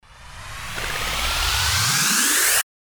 FX-1826-RISER
FX-1826-RISER.mp3